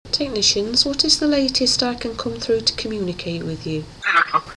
This was again recorded with just the Sony B300 and a reversed Russian background file